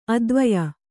♪ advaya